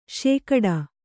♪ śekaḍa